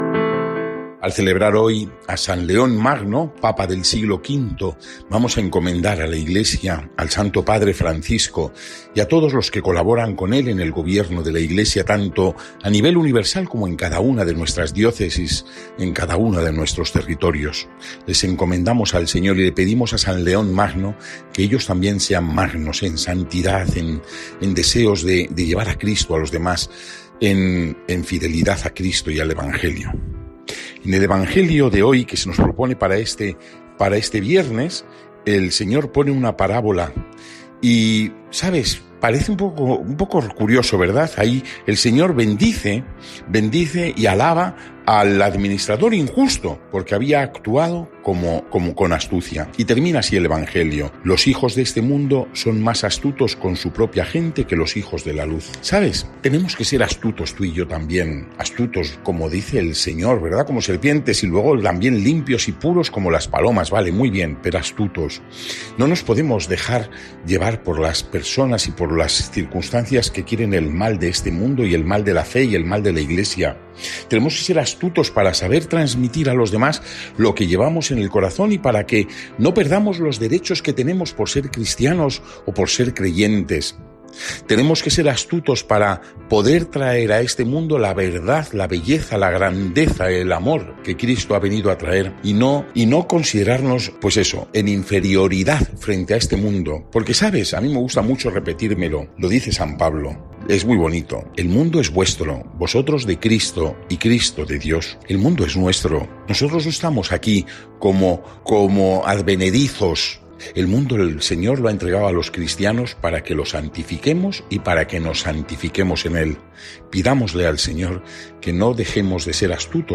Evangelio del día